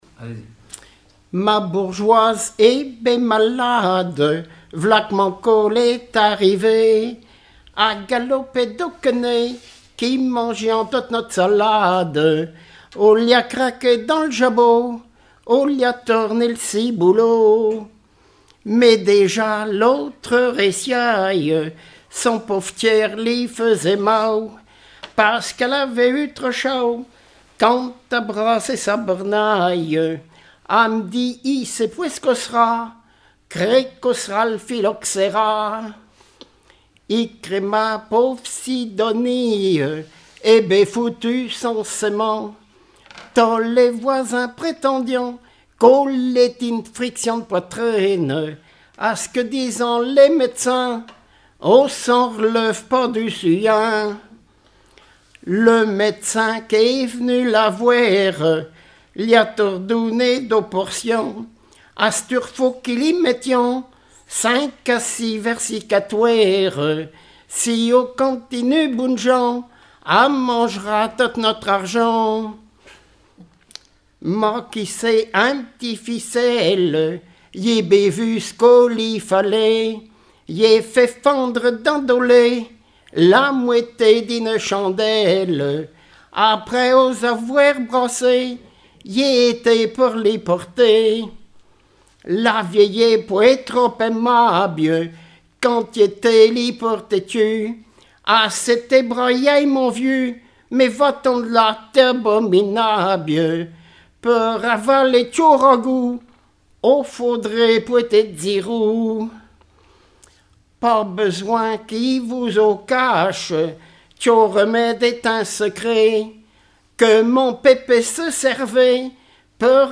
Patois local
enregistrement d'un collectif lors d'un regroupement cantonal
Pièce musicale inédite